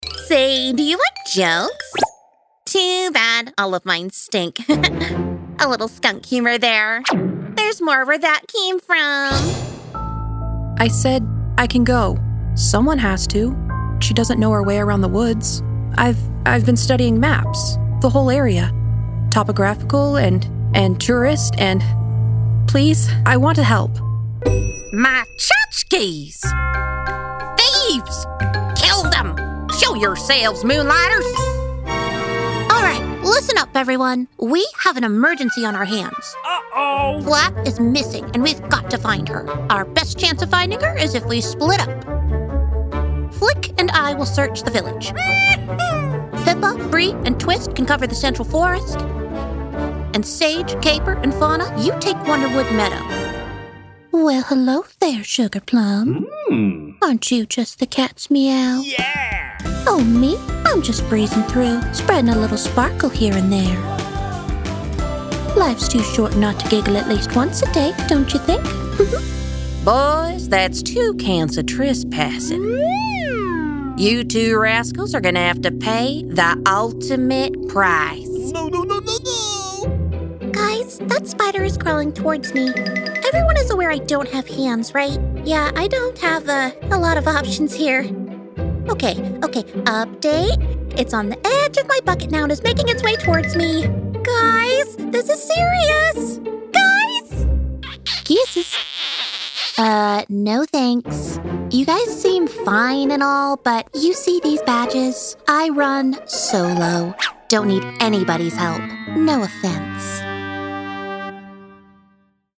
Animation Demo
English - Southern U.S. English
North American (general)
Child
Teen
Young Adult